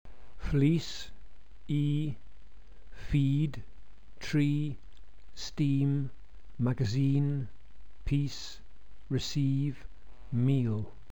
English tense vowels
FLEECE